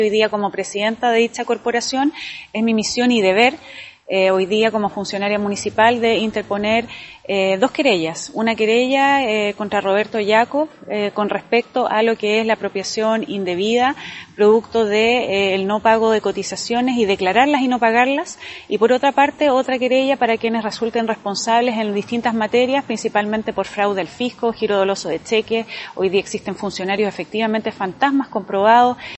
“Es mi misión y deber hoy, como funcionaria municipal, de interponer dos querellas (…) hoy existen funcionarios fantasmas”, explicó la alcaldesa Daniela Norambuena.